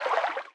Sfx_creature_symbiote_swim_slow_05.ogg